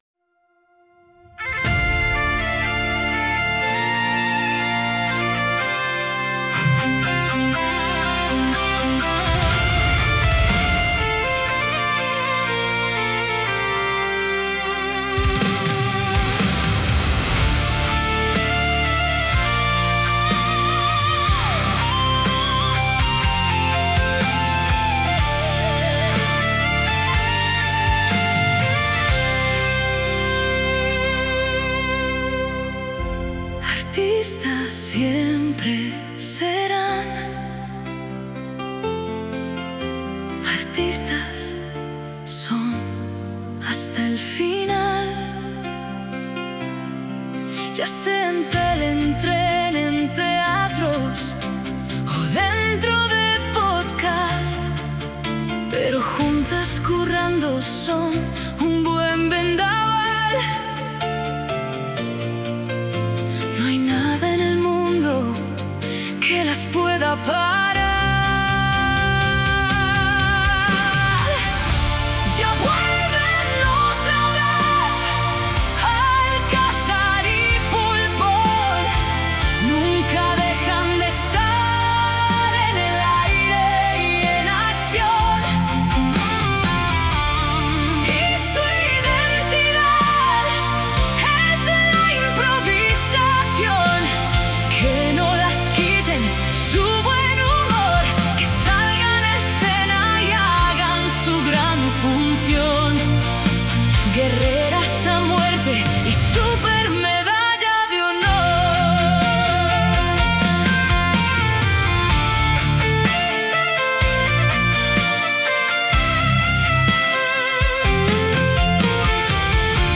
Alguien, mientras veía la obra, os ha creado una balada para vosotras, pensándola en la cabeza.